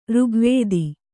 ♪ řgvēdi